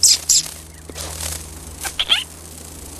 • CLOSE BIRD SQUEAKING.wav
CLOSE_BIRD_SQUEAKING_Co3.wav